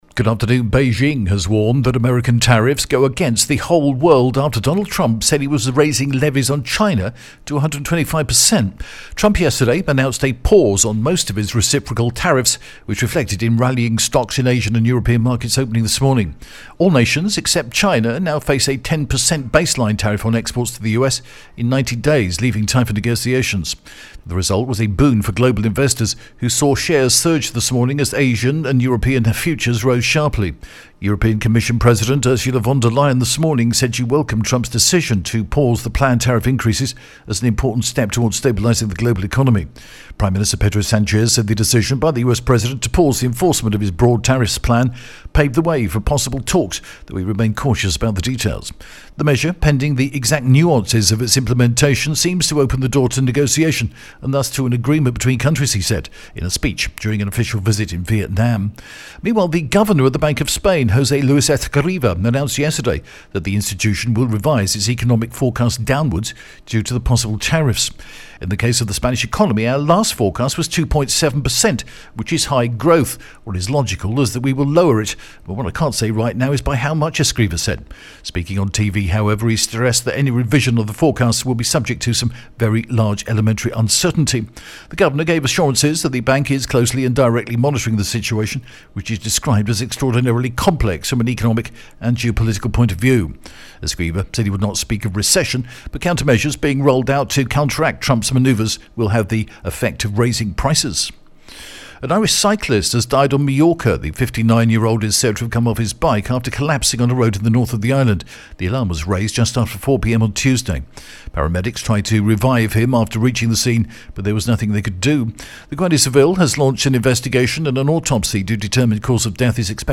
The latest Spanish news headlines in English: April 10th 2025